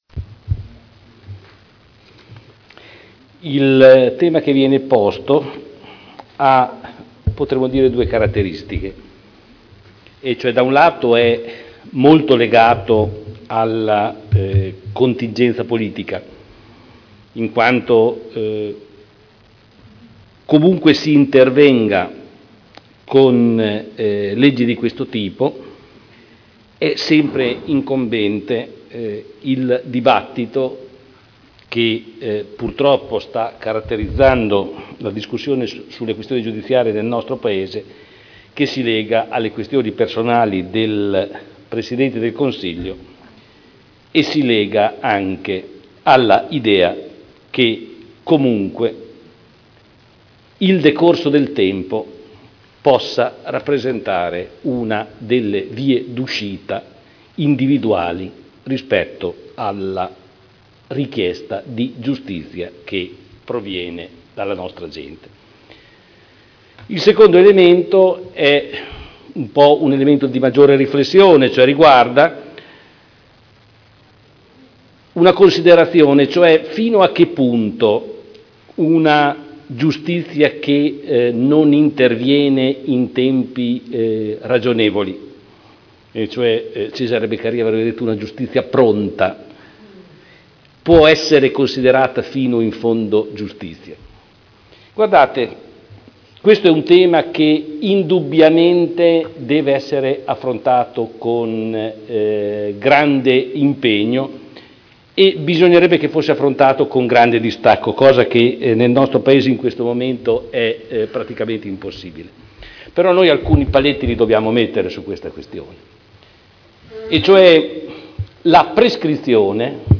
Seduta del 21/07/2011. Risponde a Interrogazione dei consiglieri Trande e Urbelli (P.D.) avente per oggetto: “Legge sulla “Prescrizione breve”: quali conseguenze sono ipotizzabili sulla sicurezza del nostro territorio?” – Primo firmatario consigliere Trande (presentata l’1 aprile 2011 - in trattazione il 21.7.2011)